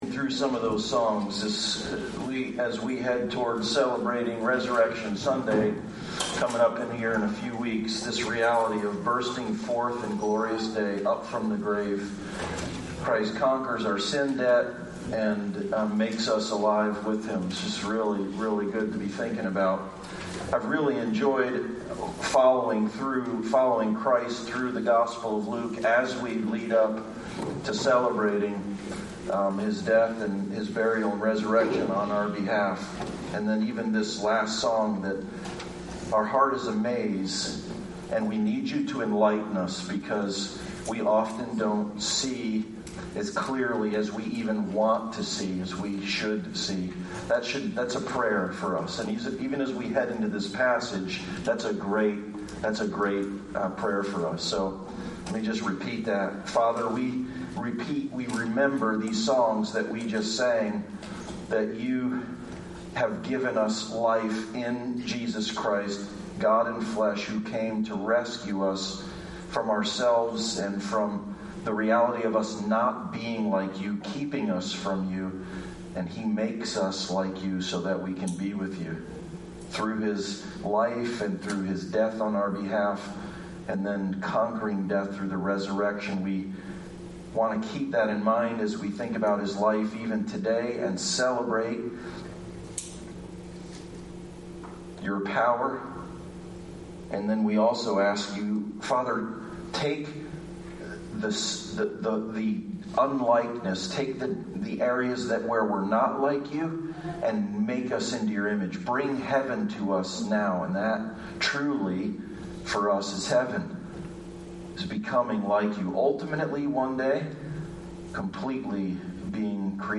Passage: Luke 18:18-30 Service Type: Sunday Service « Snapshots of Jesus